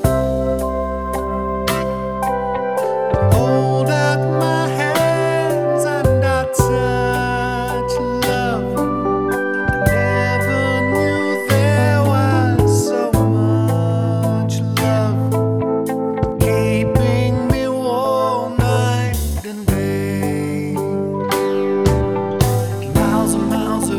One Semitone Down Pop (1970s) 4:09 Buy £1.50